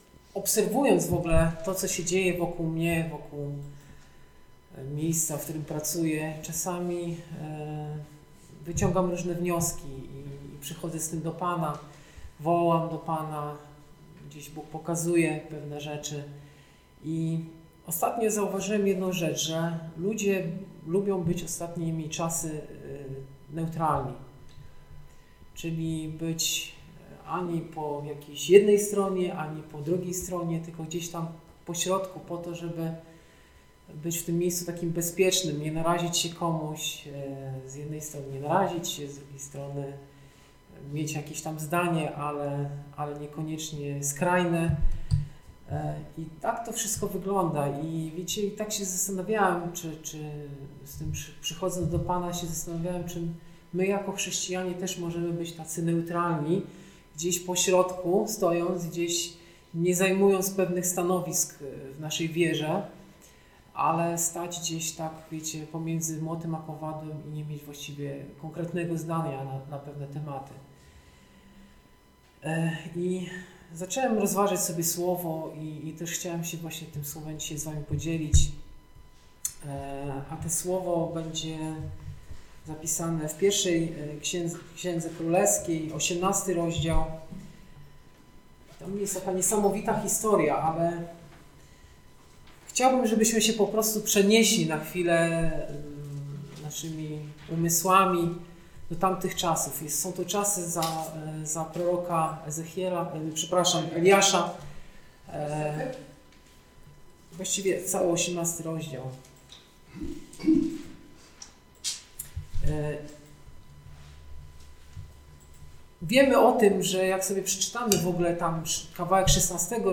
Kazanie
Posłuchaj kazań wygłoszonych w Zborze Słowo Życia w Olsztynie